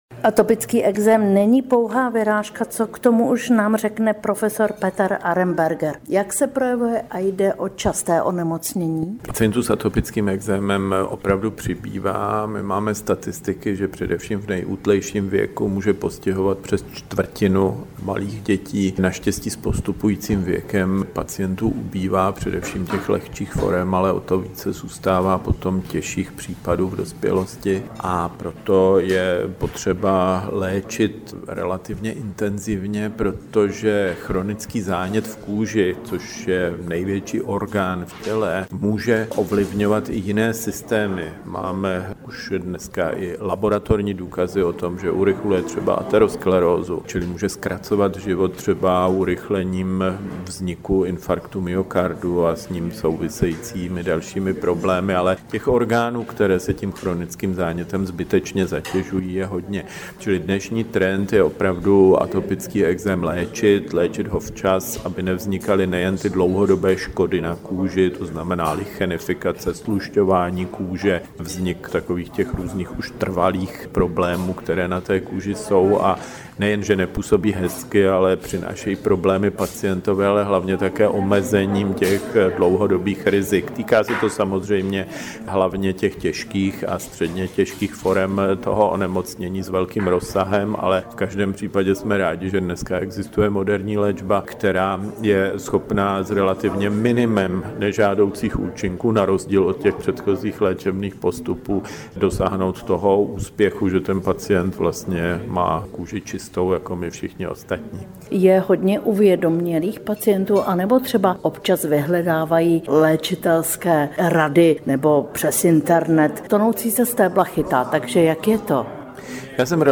AUDIO rozhovor